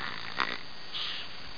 Snore.mp3